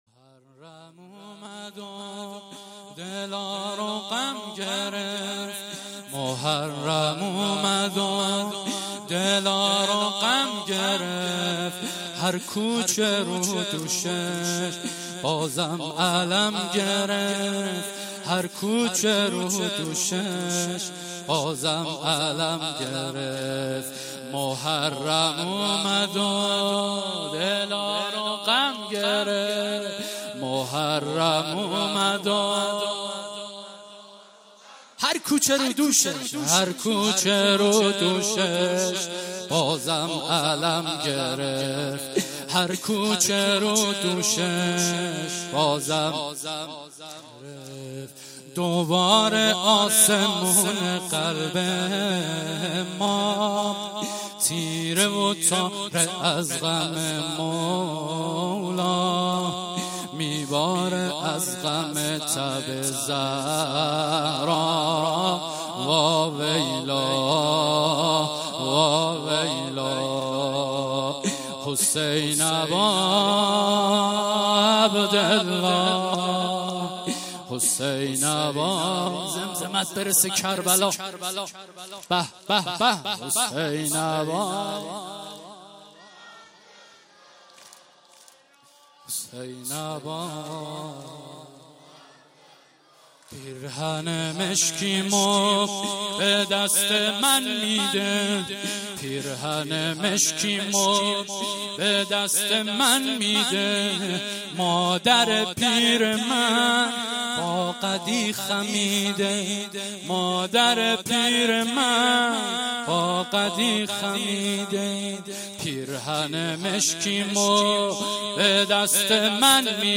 خیمه گاه - هیأت جوانان فاطمیون همدان - شب اول (مداحی واحد 2)
مسجد شهید مدرس